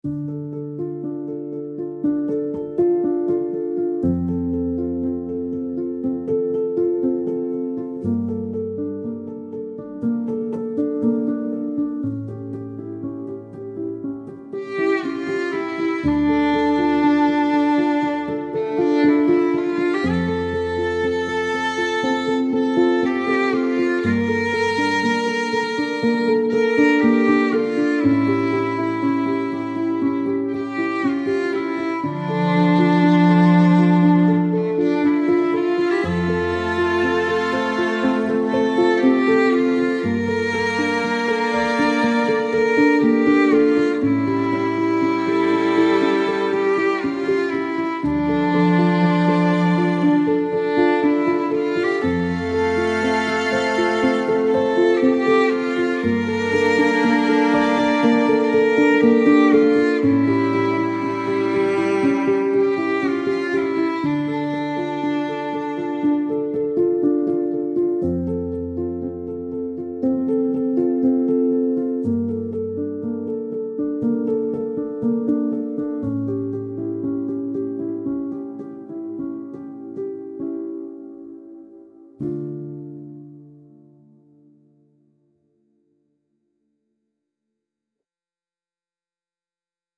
chamber music with violin and cello duet in a warm candlelit atmosphere